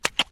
Звуки клея
Звук клея на бумаге